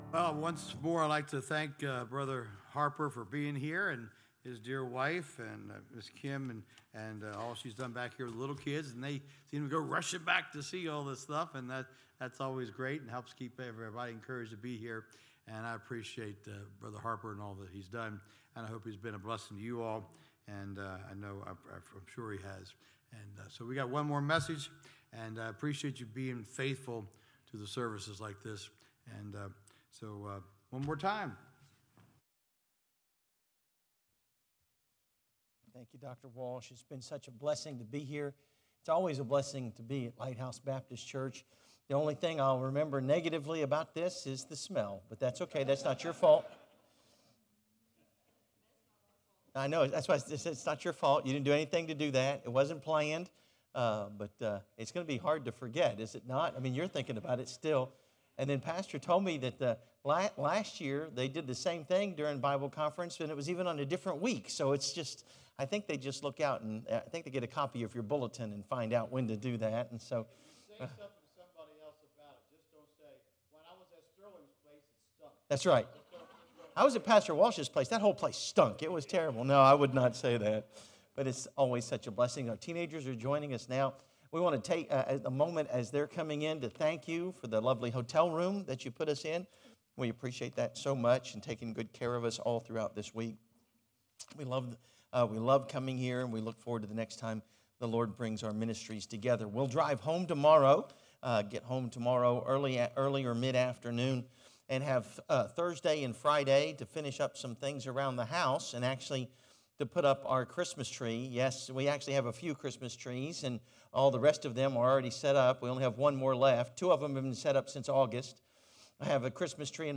Passage: Acts 4 Service Type: Fall Bible Conference